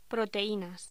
Locución: Proteínas
voz
Sonidos: Voz humana